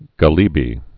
(gə-lēbē)